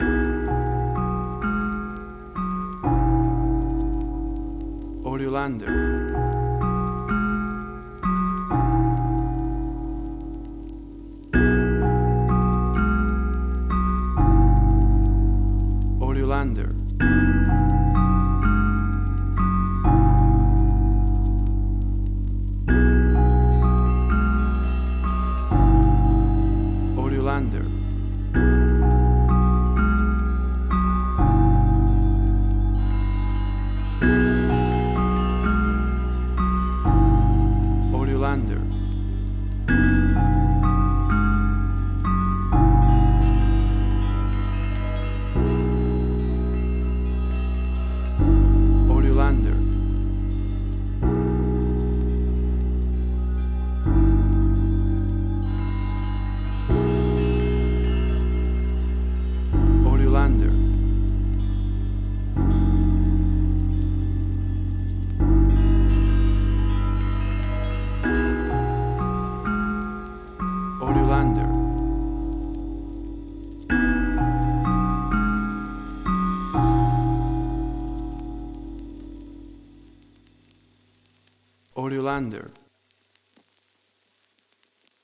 Asian Ambient.
Tempo (BPM): 43